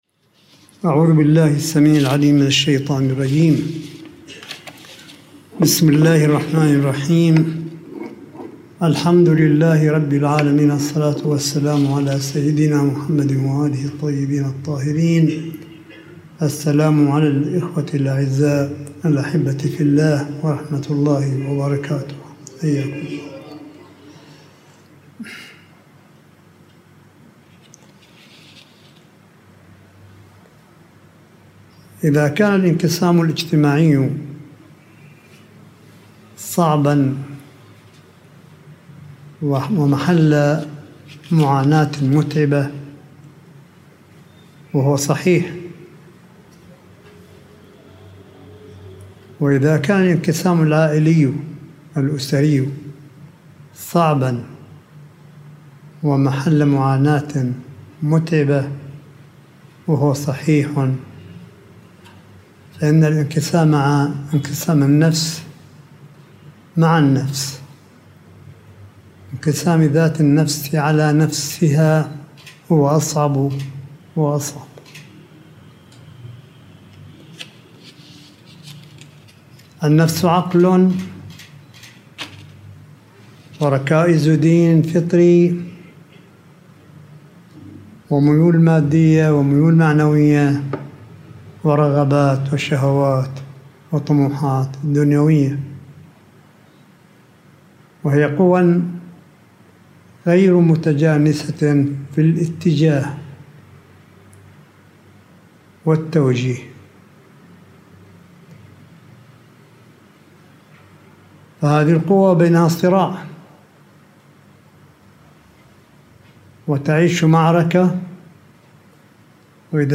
ملف صوتي لكلمة سماحة آية الله الشيخ عيسى أحمد قاسم في المجلس القرآني الذي عُقد في منزل سماحته بقم المقدسة ليلة السبت وسط حضور المؤمنين – 27 رمضان 1443هـ / 29 أبريل 2022مـ